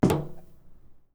grunk / assets / sfx / footsteps / metal / metal10.wav
metal10.wav